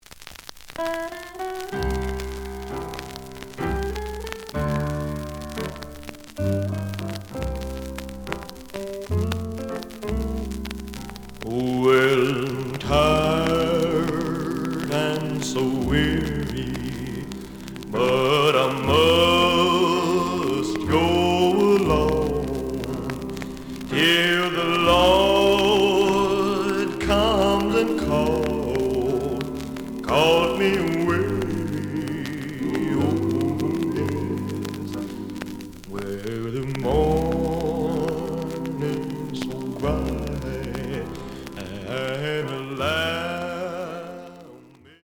The audio sample is recorded from the actual item.
●Genre: Rhythm And Blues / Rock 'n' Roll
Some noise on both sides.